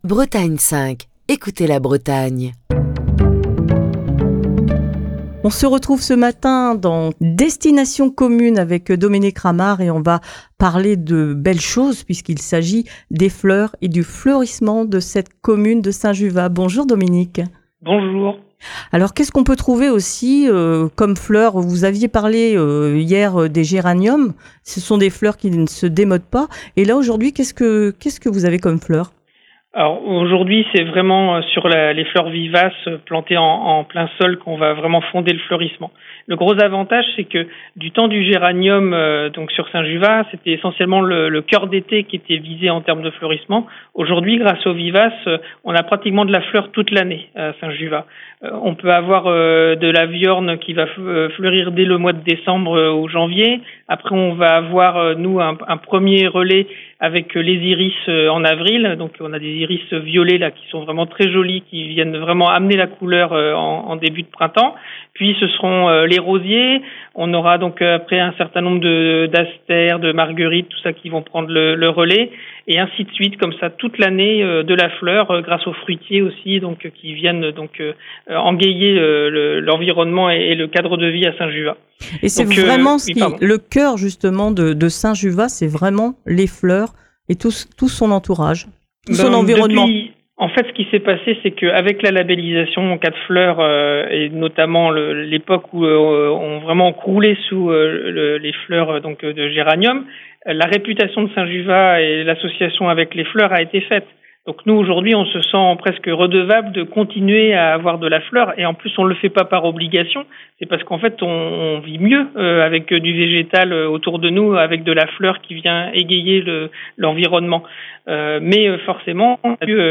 Émission du 20 septembre 2023. Destination commune prend la direction Saint-Juvat près de Dinan dans les Côtes-d'Armor.